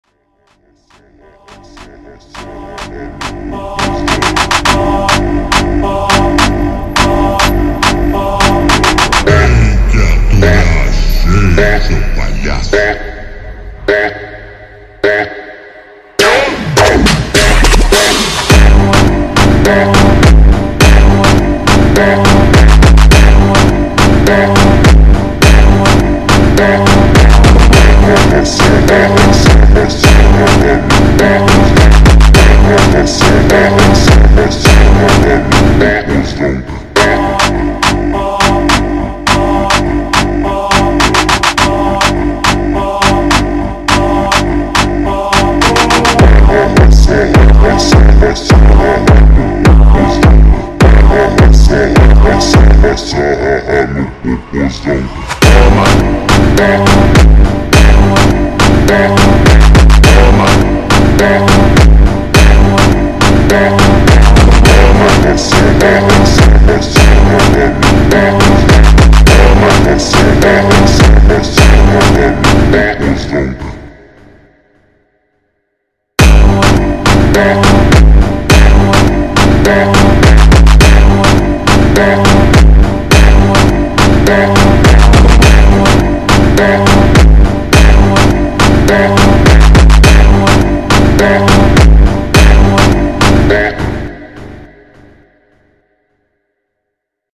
в замедленном варианте